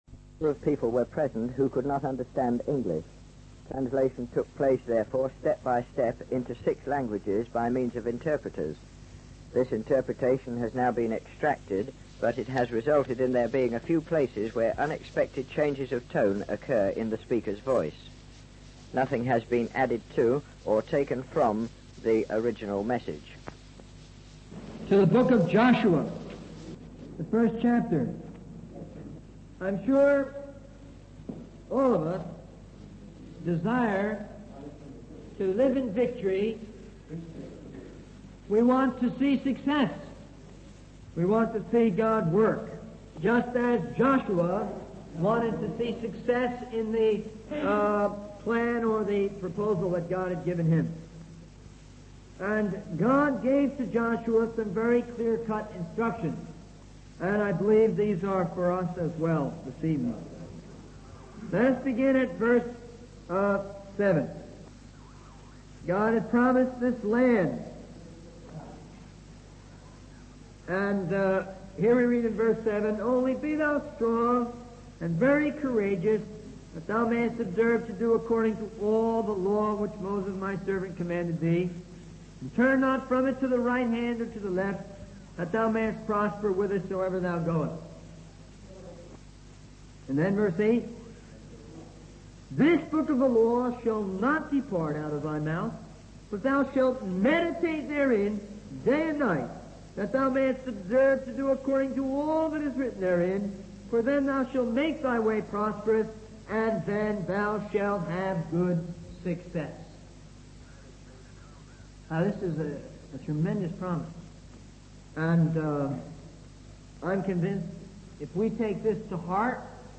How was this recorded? This interpretation has now been extracted, but it has resulted in there being a few places where unexpected changes of tone occur in the speaker's voice.